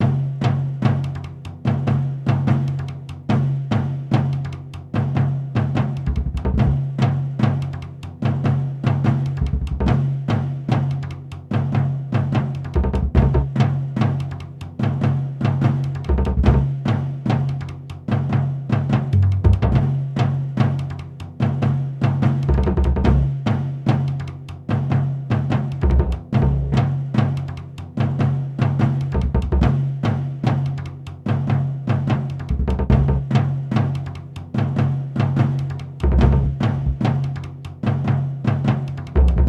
Tambores étnicos (bucle)
tambor
étnico
repetitivo
ritmo